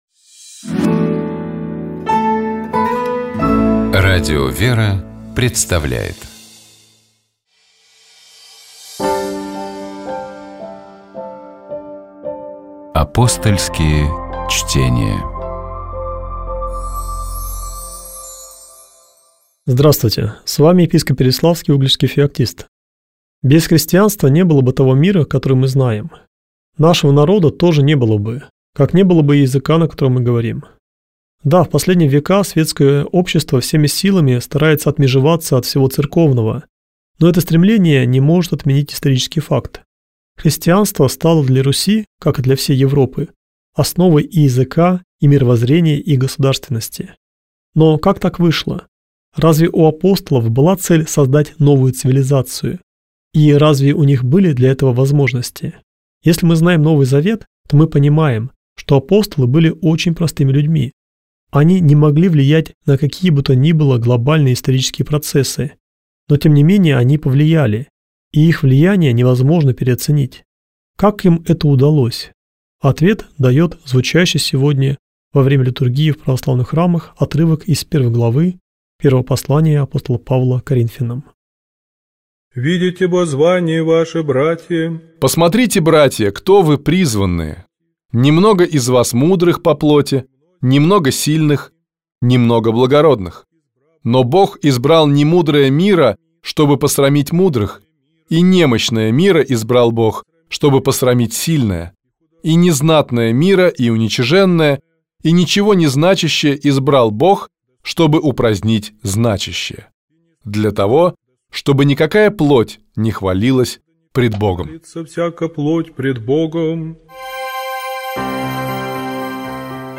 Каждую пятницу ведущие, друзья и сотрудники радиостанции обсуждают темы, которые показались особенно интересными, важными или волнующими на прошедшей неделе.